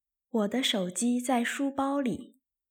Wǒ de shǒu jī zài shū bāo lǐ
ウォ デァ ショウ ジー ザイ シュ バオ リー